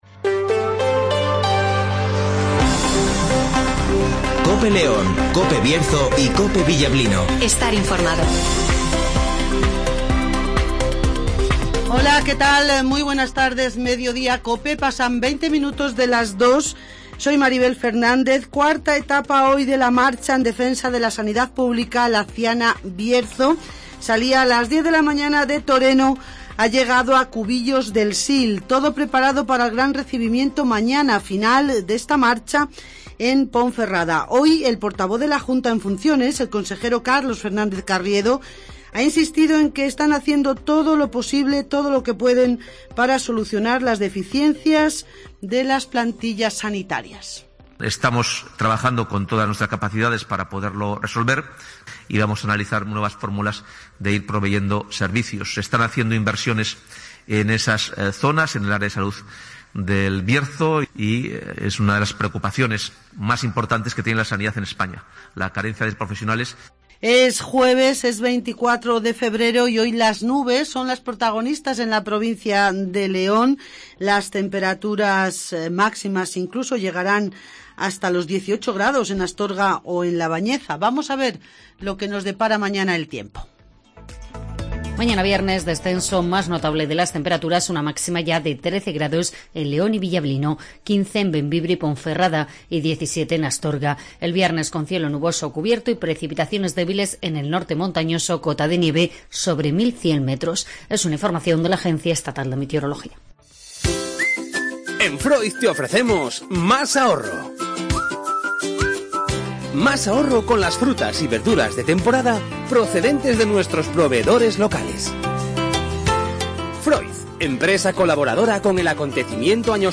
- Ester Muñóz ( Delegada Territorial de la Junta en León )
- Manolo Quijano ( Componente del Grupo Leones " Café Quijano " )
- Canción del Nuevo Disco de " Café Quijano "